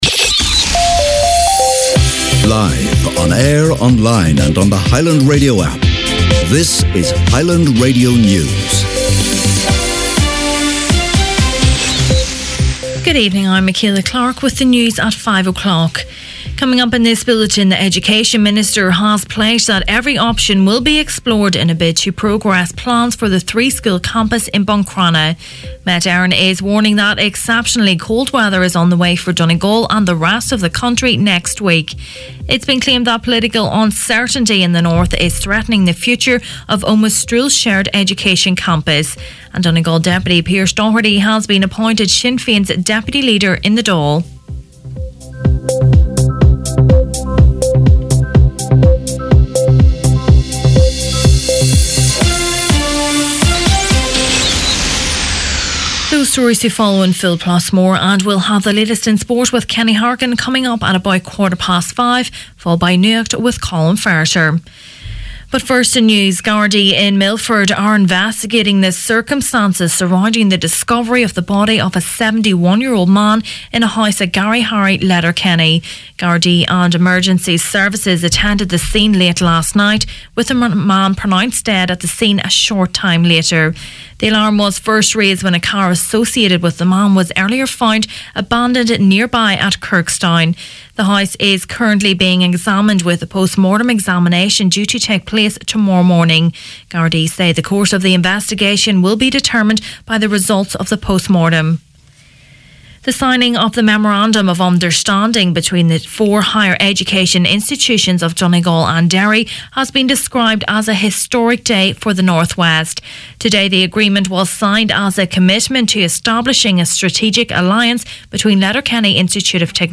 Main Evening News, Sport, Nuacht and Obituaries Friday 23rd February